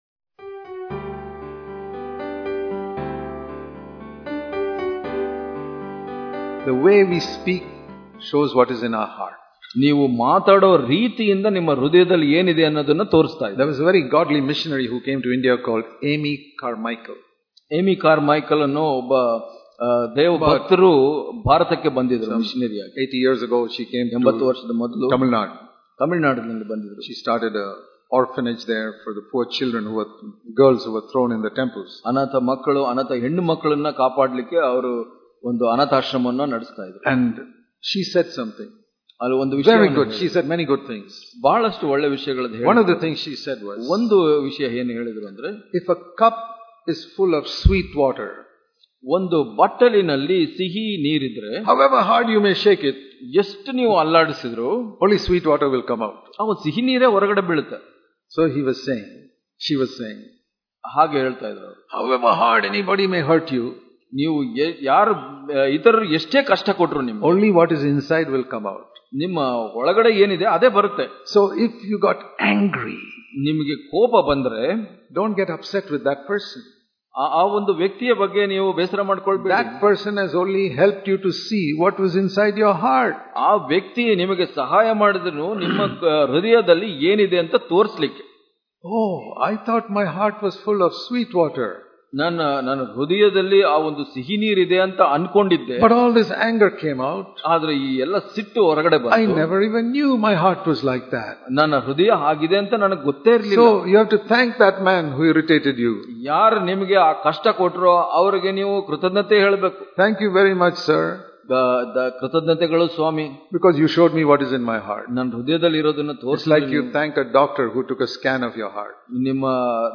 Daily Devotions